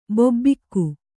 ♪ bobbikku